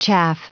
Prononciation du mot chaff en anglais (fichier audio)
Prononciation du mot : chaff